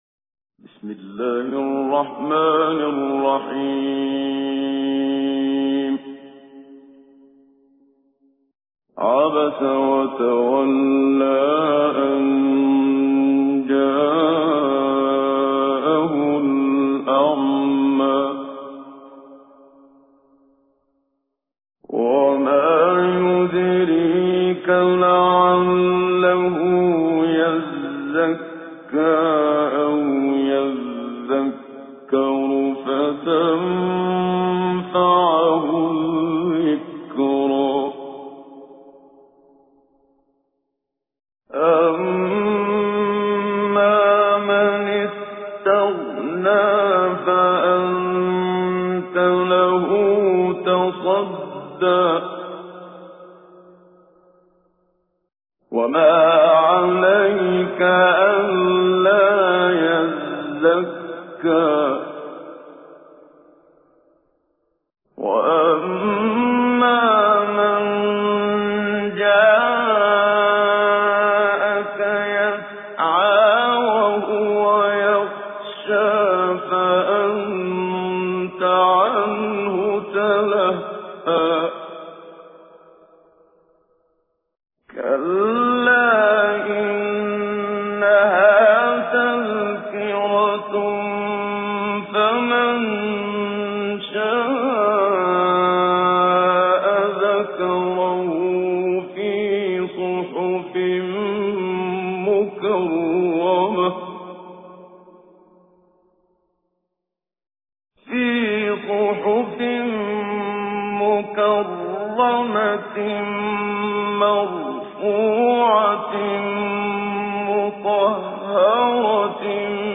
تجويد
سورة عبس الخطیب: المقريء الشيخ محمد صديق المنشاوي المدة الزمنية: 00:00:00